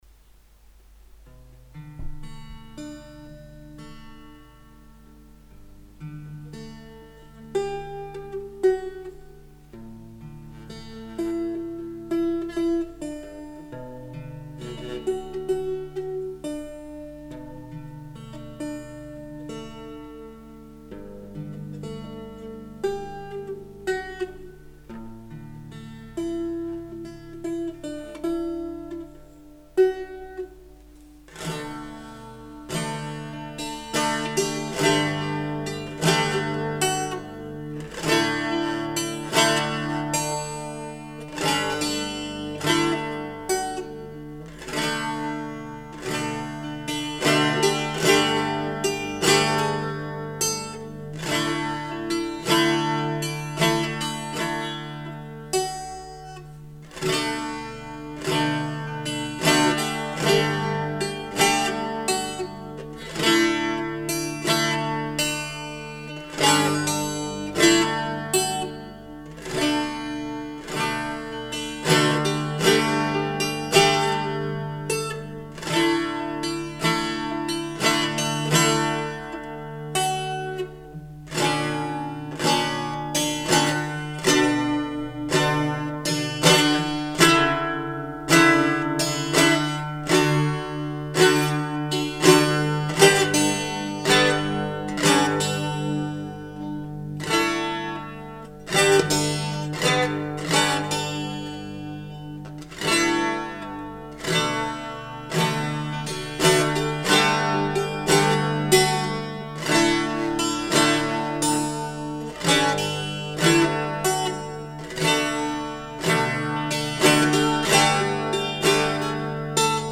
Daher folgt hier erst einmal ein simples Hörbeispiel des letzten Songs von „Topaz“ mit dem Titel „Roses of Eostre„, instrumental, eingespielt mit einem handelsüblichen kleinen Sennheiser Tischmikro, ohne Gesang und teils noch etwas unsicher, da live.
Zu hören ist eine Irish Bouzouki.